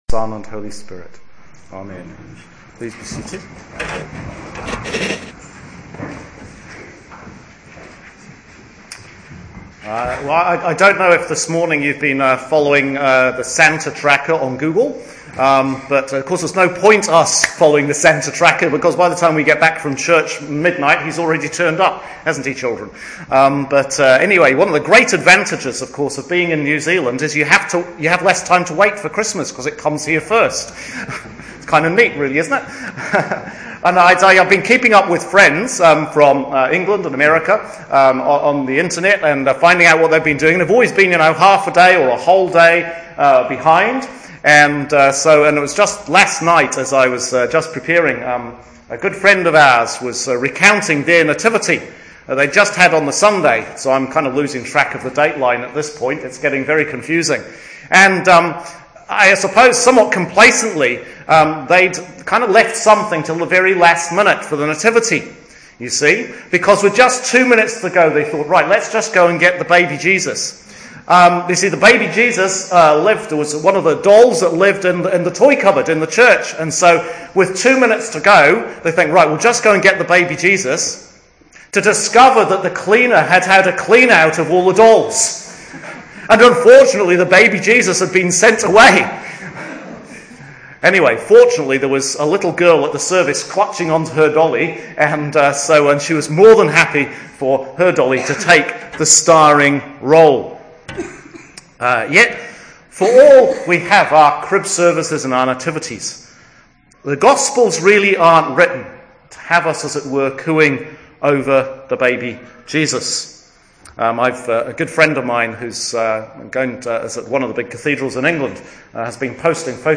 Recording of the Sermon as delivered